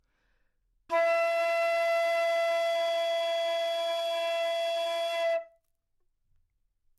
长笛单音 " 单音的整体质量 长笛 E4
描述：在巴塞罗那Universitat Pompeu Fabra音乐技术集团的goodsounds.org项目的背景下录制。单音乐器声音的Goodsound数据集。 instrument :: flutenote :: Eoctave :: 4midi note :: 52microphone :: neumann U87tuning reference :: 442goodsoundsid :: 9dynamic_level :: mf
标签： 纽曼-U87 单注 E4 多重采样 好声音 长笛
声道立体声